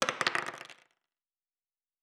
pgs/Assets/Audio/Fantasy Interface Sounds/Dice Multiple 10.wav at master
Dice Multiple 10.wav